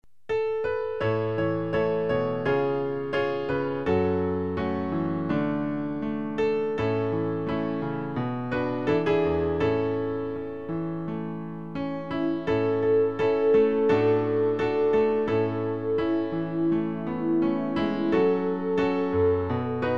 Klavier-Playback zur Begleitung der Gemeinde MP3 Download
Klavier-Playback